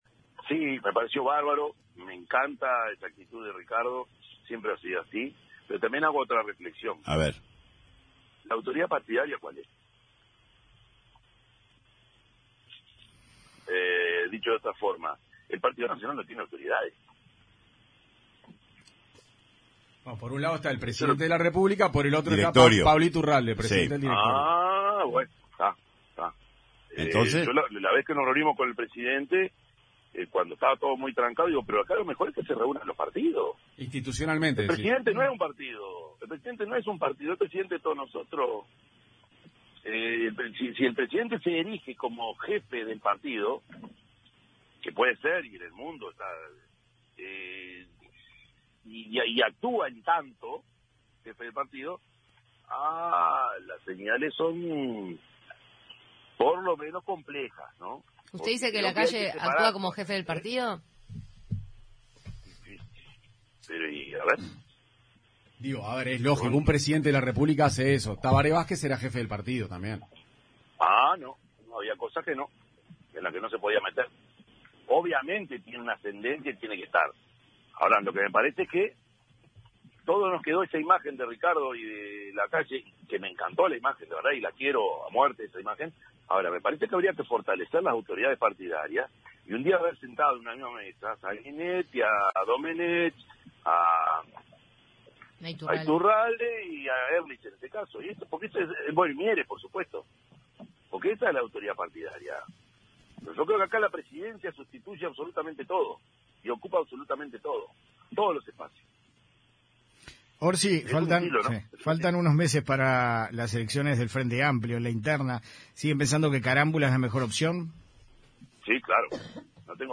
El intendente de Canelones, Yamandú Orsi, valoró la actitud «bárbara» de Ricardo Ehrlich, coordinador interino del Frente Amplio, de ir a reunirse con el presidente, Luis Lacalle Pou.
Escuche lo expuesto por Yamandú Orsi sobre el Partido Nacional